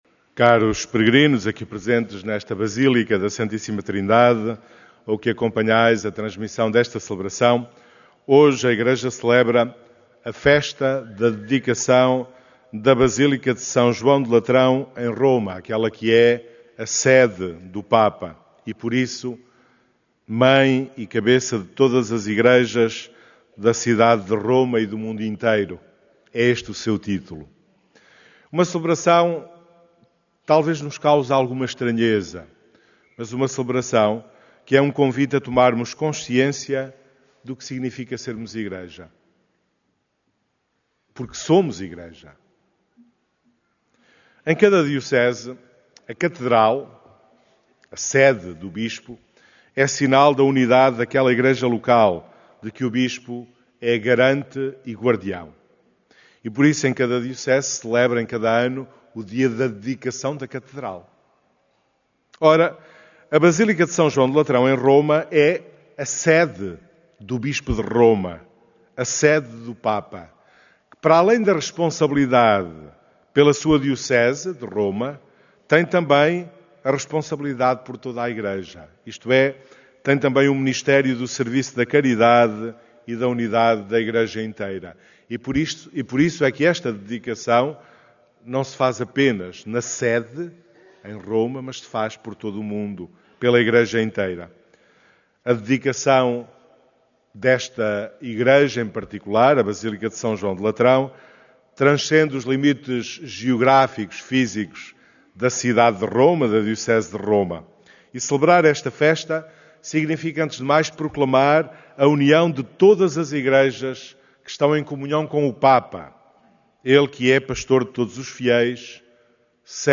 Os peregrinos participantes na missa da manhã deste domingo 9 de novembro formaram Assembleia numerosa, preencheram por completo a Basílica da Santíssima Trindade